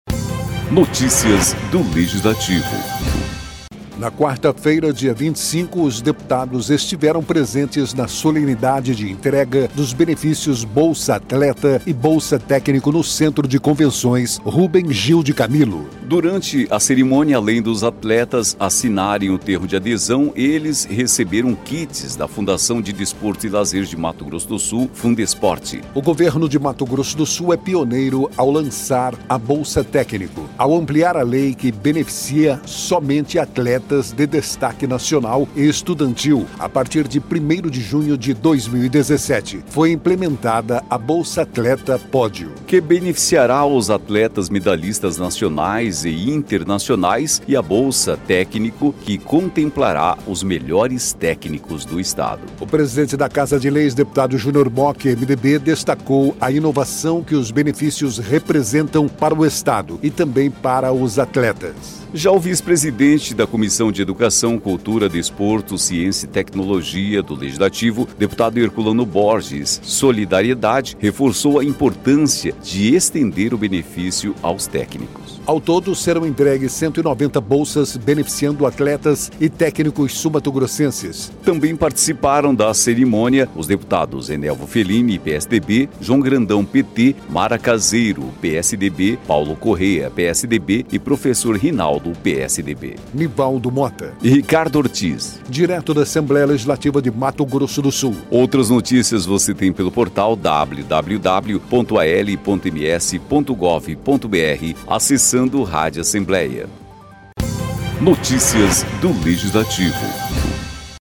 O presidente da Casa de Leis, deputado Junior Mochi (PMDB), destacou a inovação que os benefícios representam para o Estado e também para os atletas.
Locução: